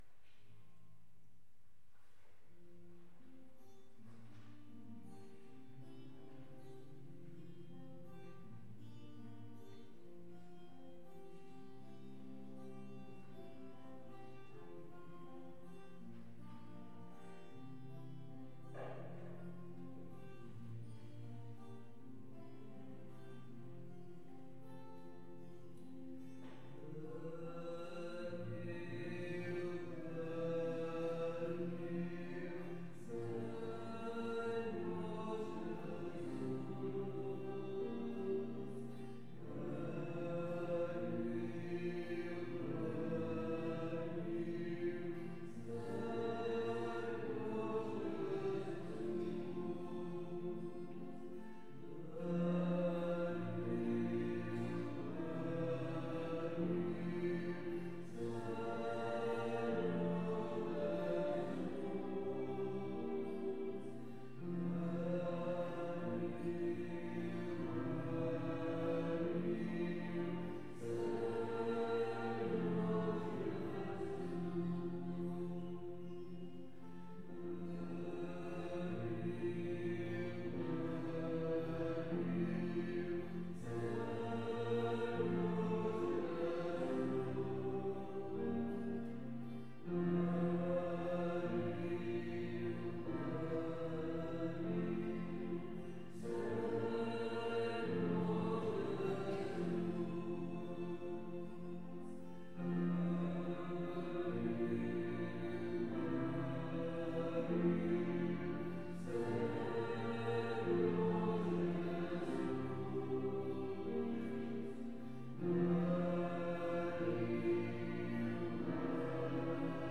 Pregària de Taizé a Mataró... des de febrer de 2001
Convent de la Immaculada - Carmelites - Diumenge 18 de desembre 2016
Vàrem cantar...